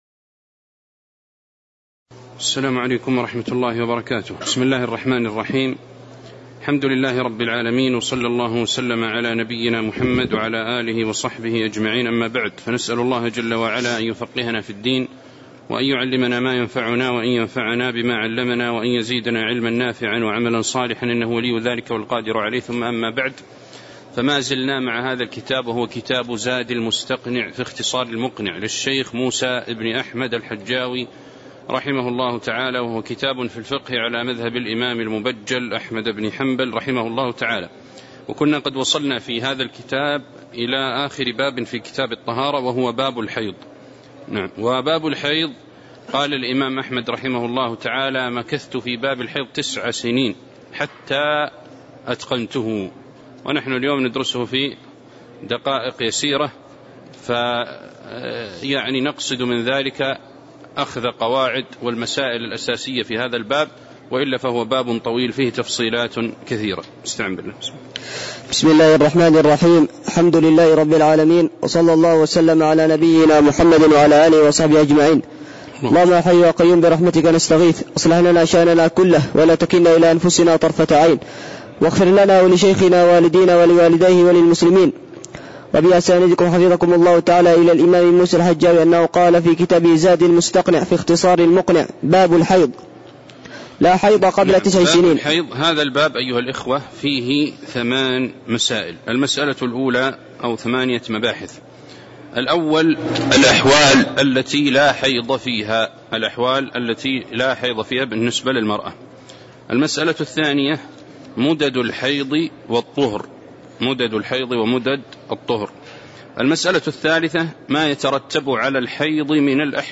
تاريخ النشر ٢١ محرم ١٤٤٠ هـ المكان: المسجد النبوي الشيخ